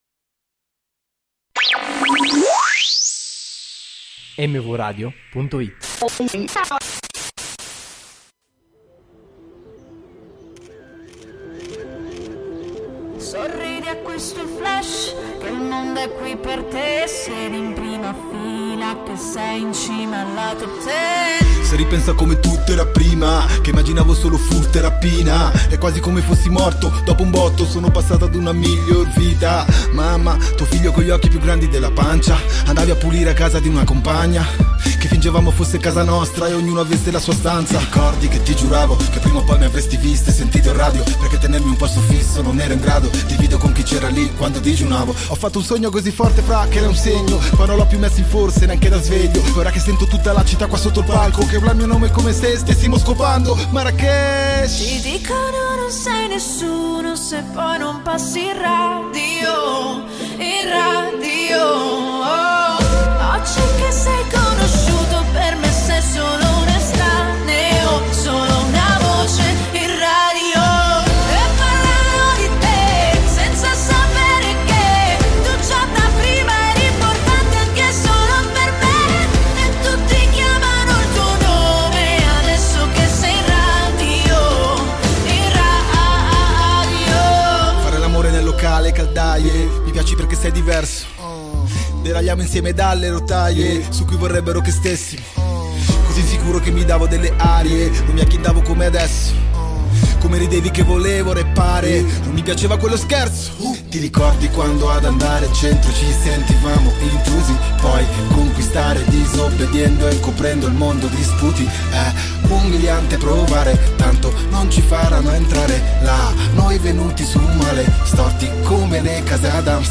Ah, il tutto trattato da 4 ragazzi delle superiori: scusate se è poco!